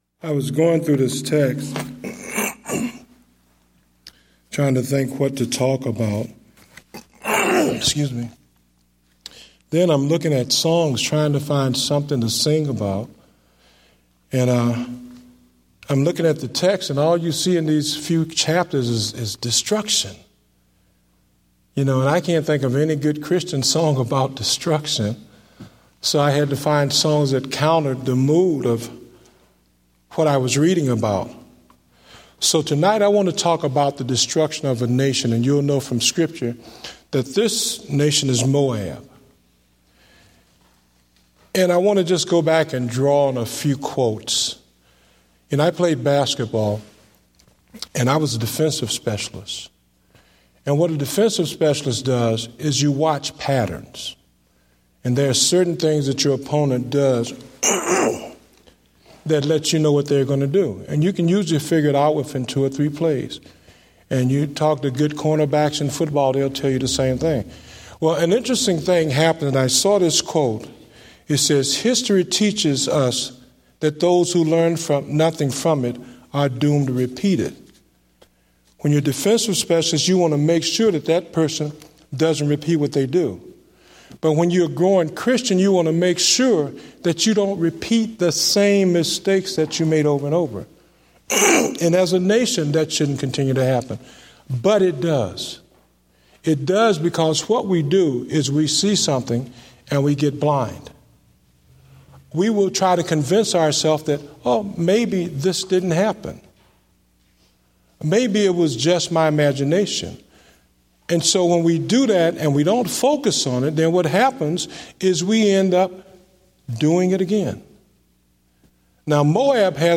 Date: October 5, 2014 (Evening Service)